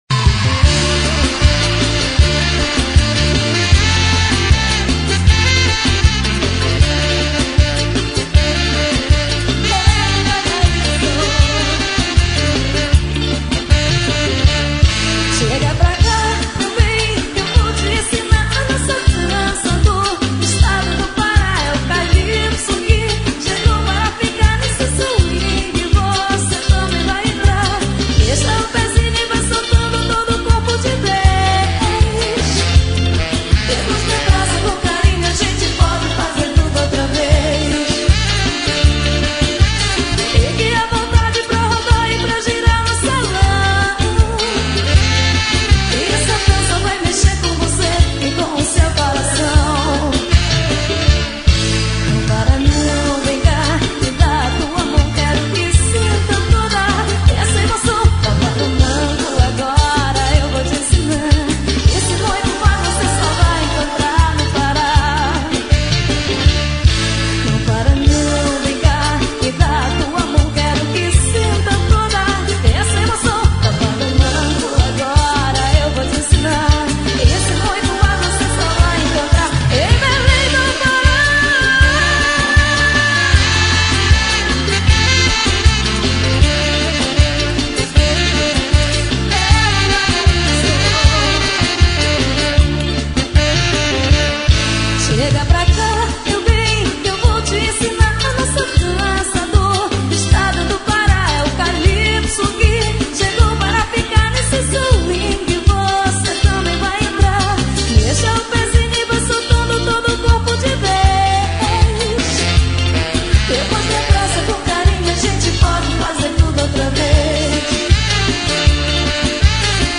Brega e Forro